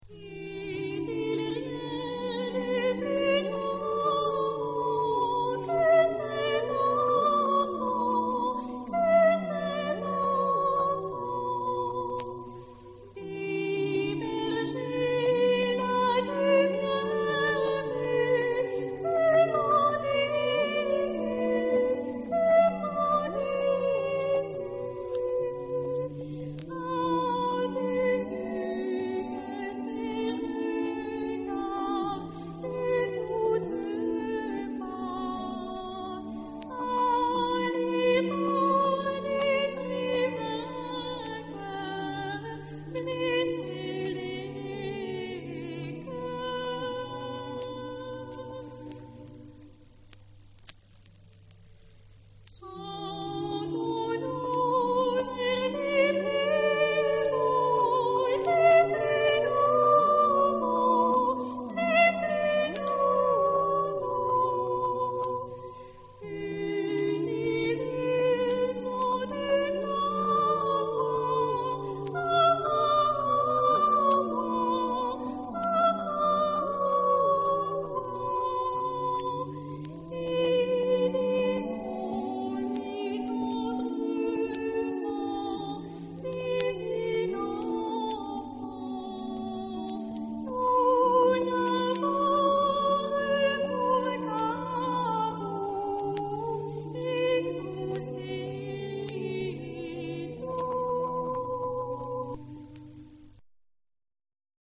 Tipo de formación coral: SAB O SAH  (3 voces Coro mixto )
Tonalidad : la menor